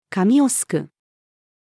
髪を梳く-female.mp3